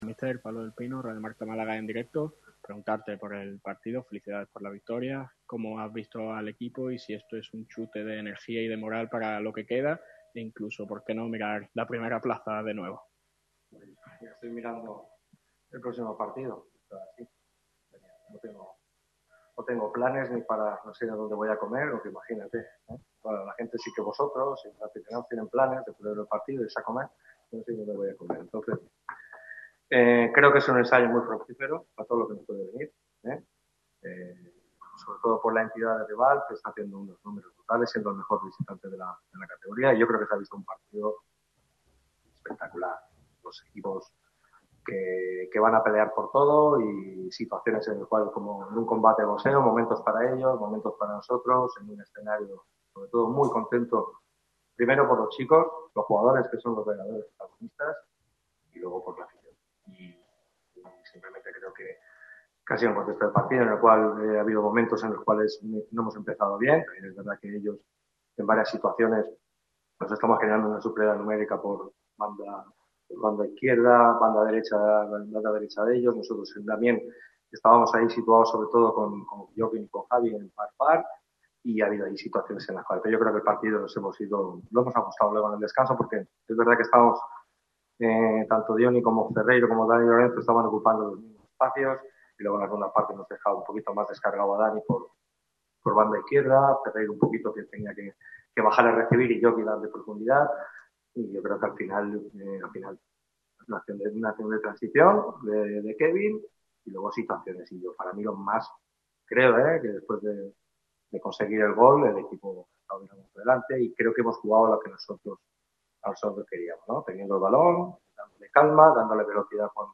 Sergio Pellicer ha atendido a los medios en la rueda de prensa post partido. Victoria de mucho peso del Málaga CF por 1-0 ante la UD Ibiza en La Rosaleda.